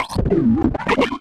AFX_DROIDTALK_1_DFMG.WAV
Droid Talk 1